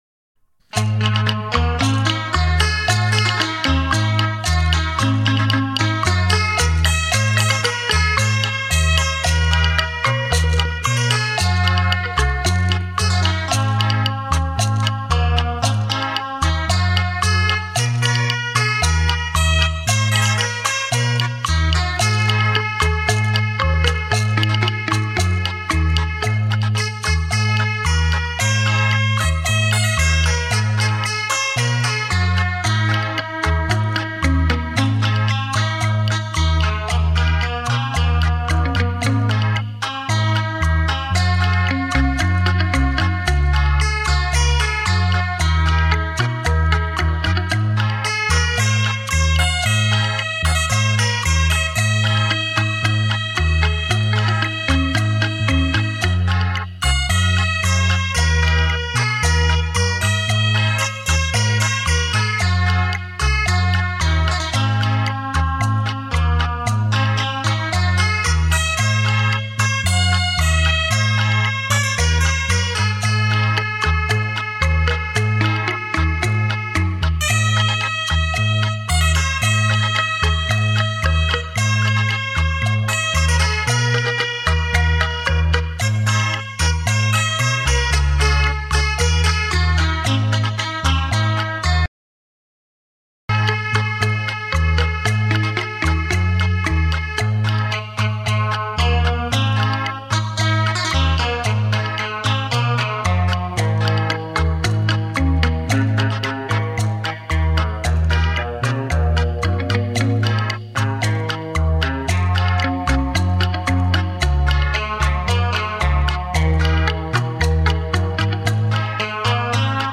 超级立体音场环绕
沉浸在这感性的旋律里